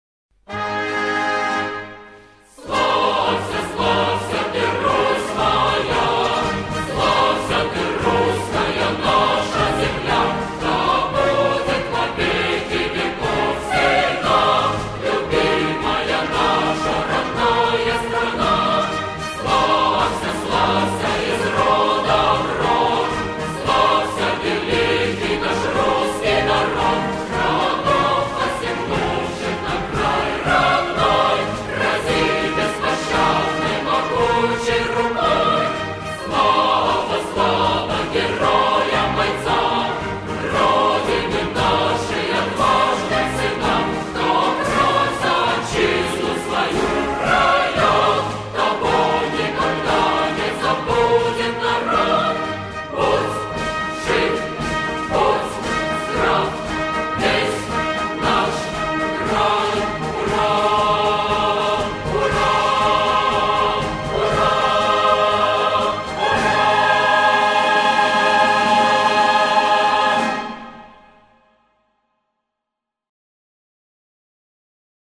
Slavsya.mp3  (размер файла: 1,15 Мб, MIME-тип: audio/mpeg ) Хор «Славься!»